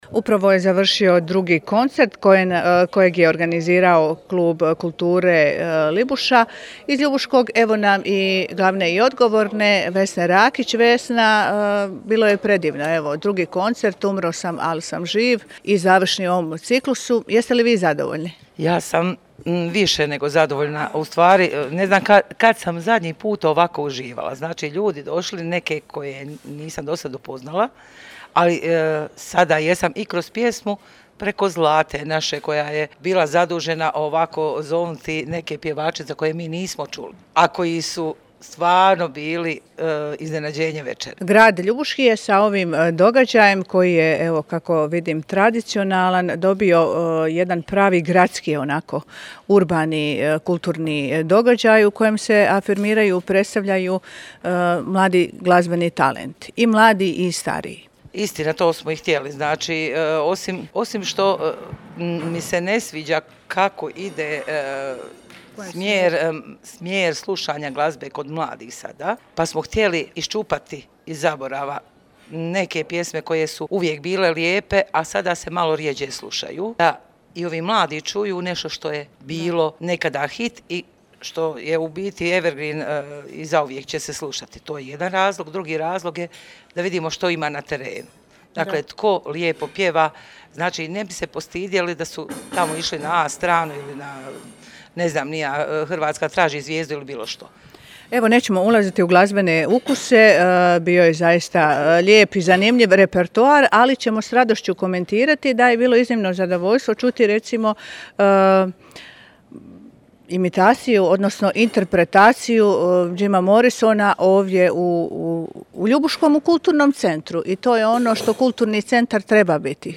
U Kulturnom centru u Ljubuškom sinoć je održan drugi koncert pod nazivom „Umro sam, al sam živ“.